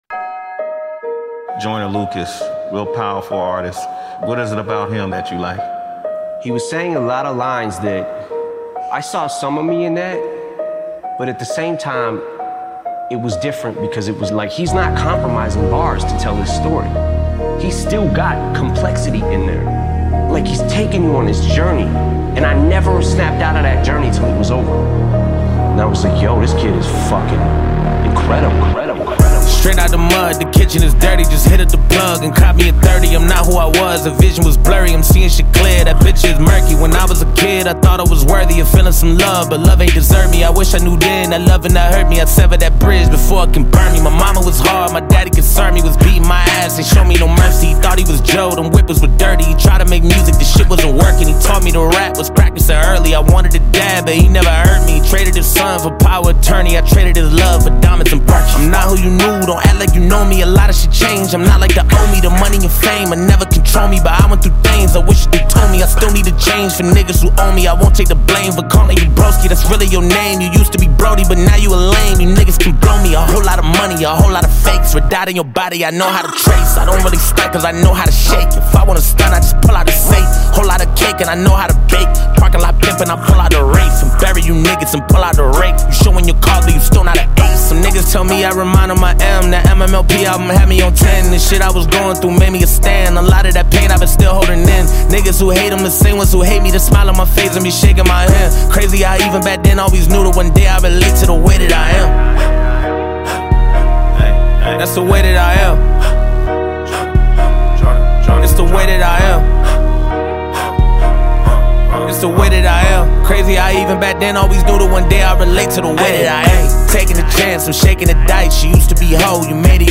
Highly gifted vocalist